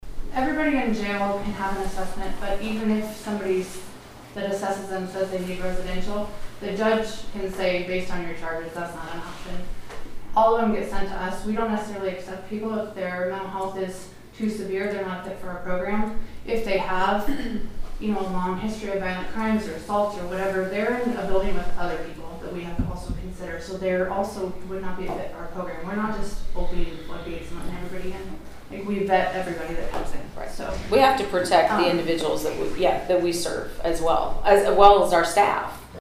(Cass Co.) The lease agreement for the old Willow Heights residential facility was the main topic of discussion at the Cass County Board of Supervisors meeting again this morning.